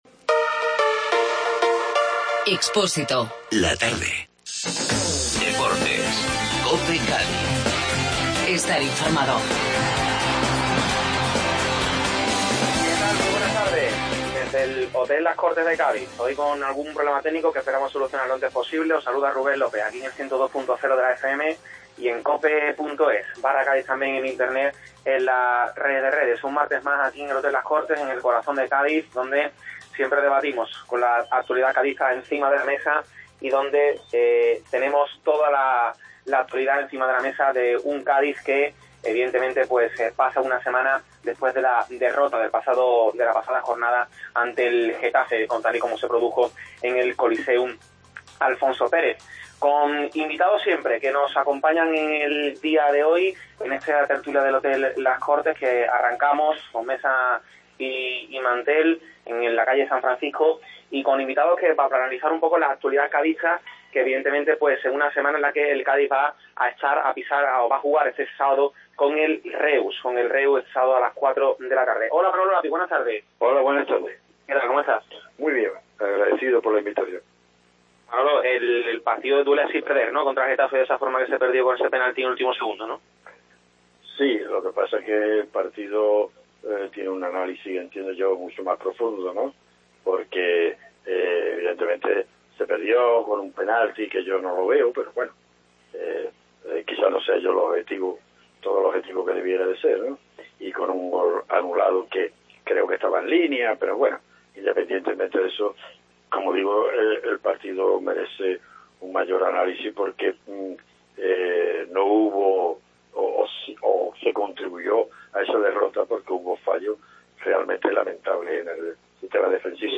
Desde el Hotel Las Cortes de Cádiz